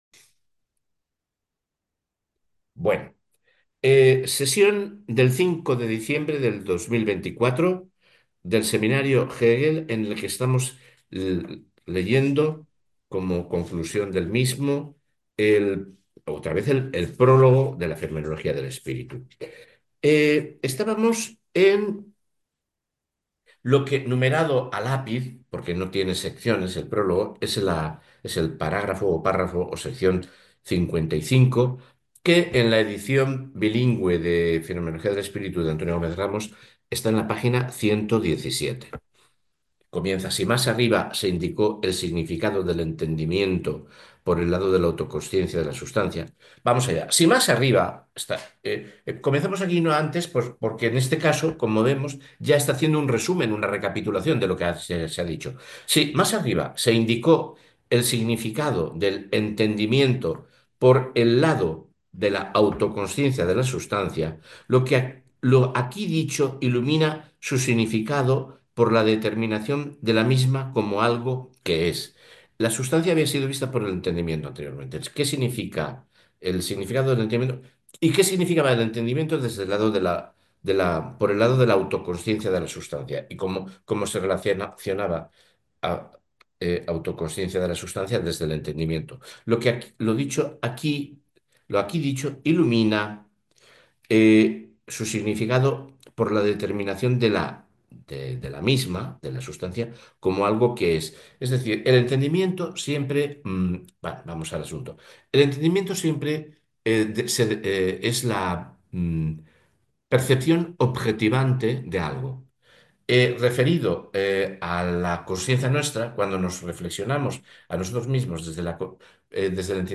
Nueva sesión sobre sobre la Fenomenología del espíritu de Hegel, dentro del seminario de Espai Marx.